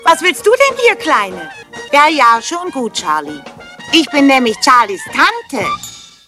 - Charlies Tante